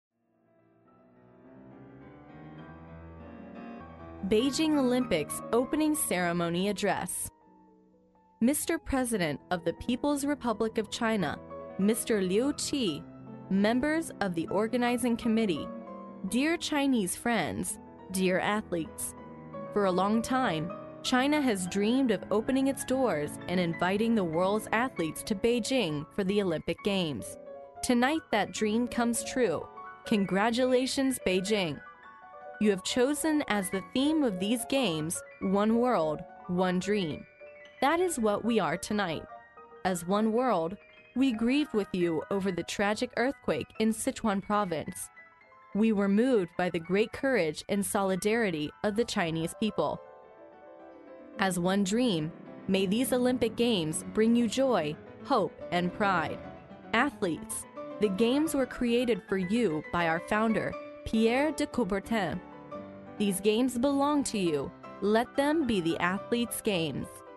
历史英雄名人演讲 第48期:北京奥运开幕式致辞(1) 听力文件下载—在线英语听力室